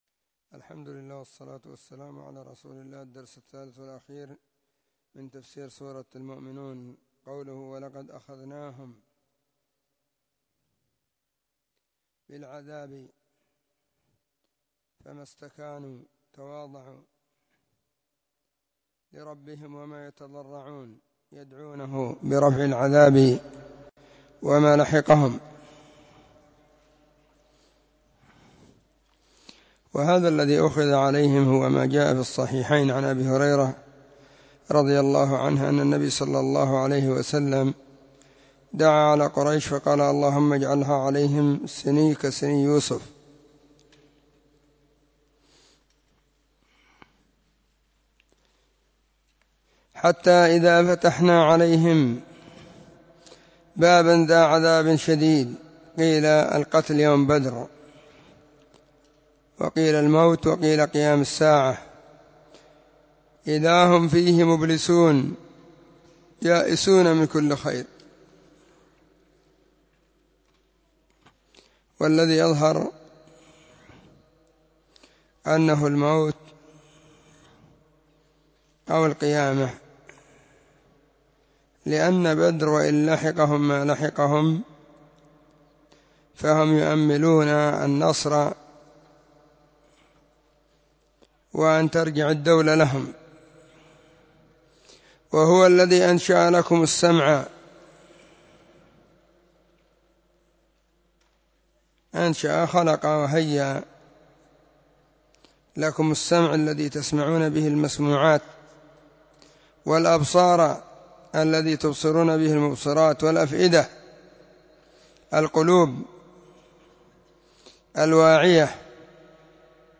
📢 مسجد الصحابة – بالغيضة – المهرة، اليمن حرسها الله.
تفسير-سورة-المؤمنون-الدرس-3.mp3